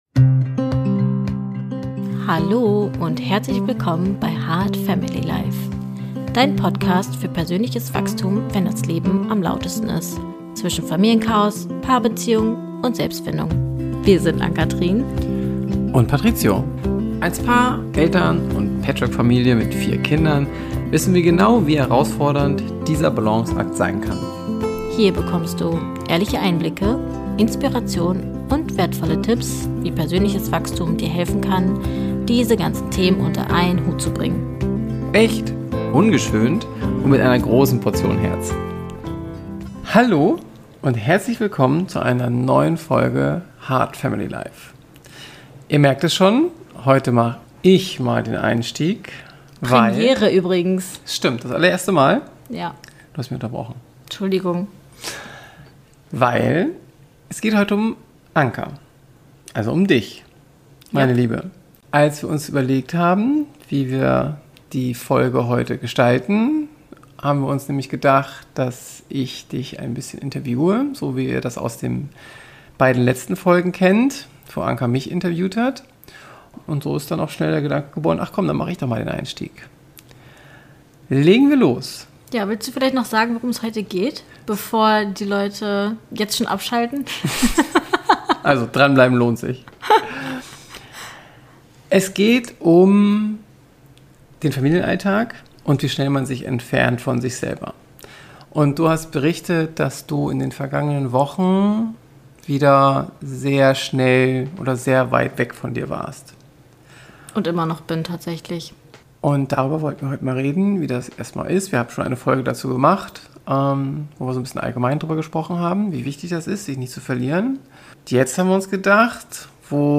Diese Folge ist ein offenes Gespräch über: die Sehnsucht nach innerer Verbindung die Scham, schlecht über sich zu denken die Erschöpfung einer Mama und Bonusmama den Mut, ehrlich hinzuschauen und erste Schritte zurück – zu Selbstwert, Selbstfürsorge und innerem Frieden.